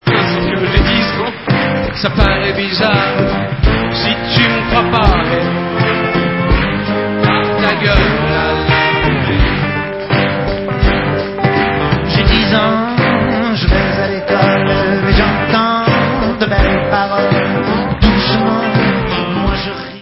sledovat novinky v kategorii Pop